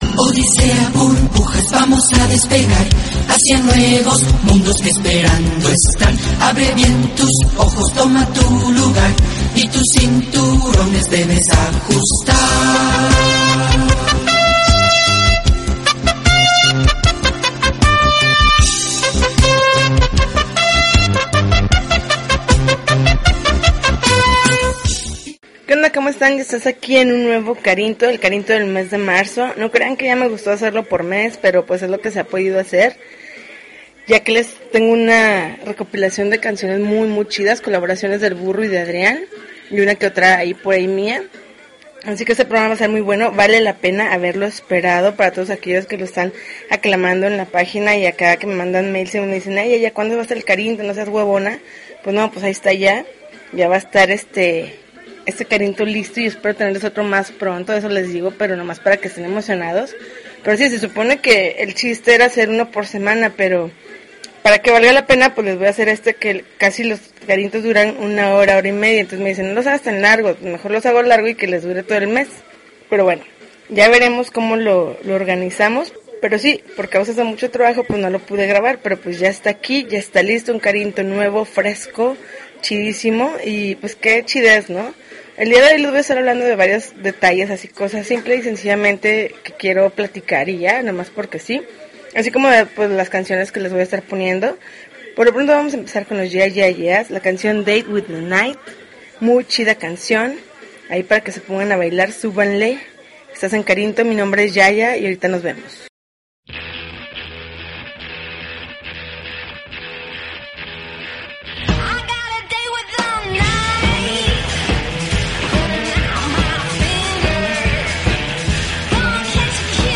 March 28, 2013Podcast, Punk Rock Alternativo